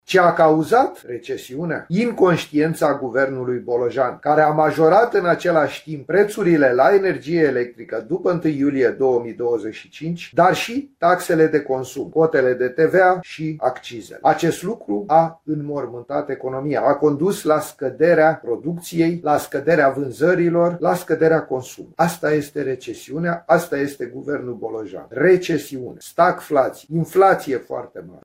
Reacție despre recesiune și din partea principalului partid de opoziție. Liderul senatorilor AUR, Petrișor Peiu, spune că măsurile de austeritate introduse de Guvernul Bolojan au cauzat situația economică actuală a țării.